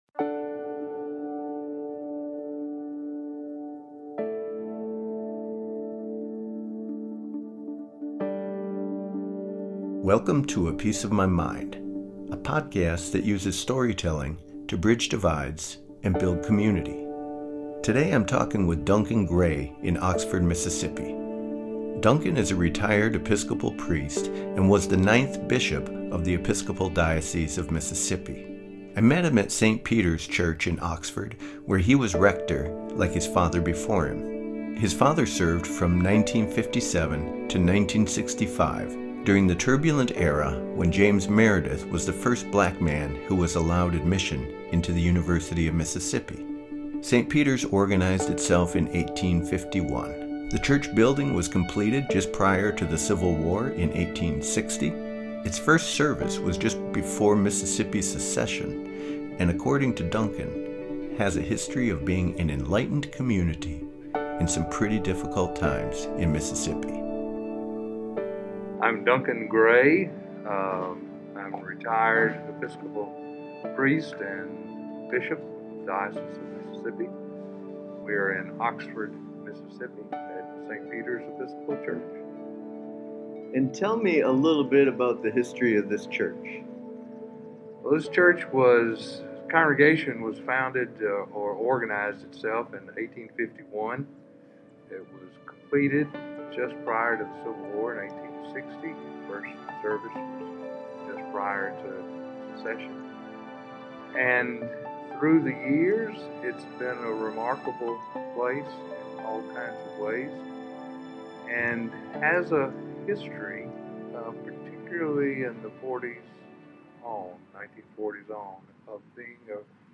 Duncan Gray is a retired Episcopal Priest and was the 9th Bishop of the Episcopal Diocese of Mississippi. I met him at St. Peter’s Episcopal church in Oxford, Mississippi, where he was rector, like his father before him. His father served from 1957 to 1965 during the turbulent era when James Meredith was the first Black man who was allowed admission into the University of Mississippi.